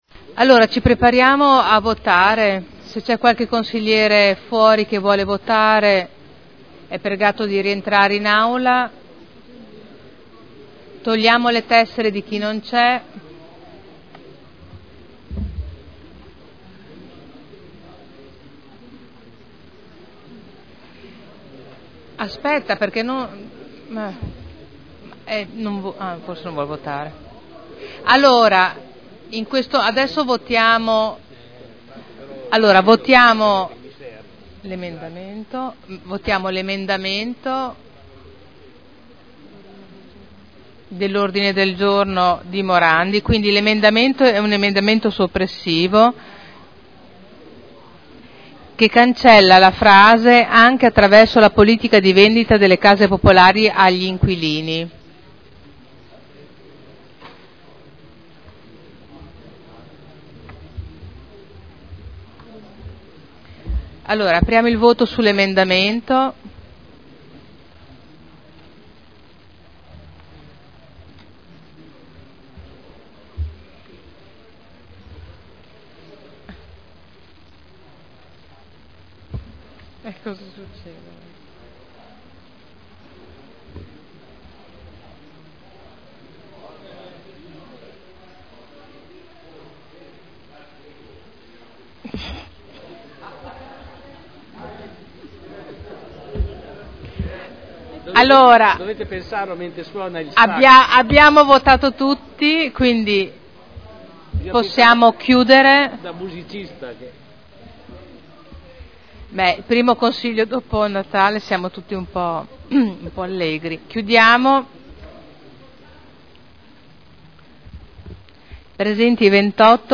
Seduta del 09/01/2012. Il Presidente Caterina Liotti mette ai voti: Emendamento all'Odg Morandi, approvato. Odg Morandi, approvato.